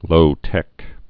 (lōtĕk)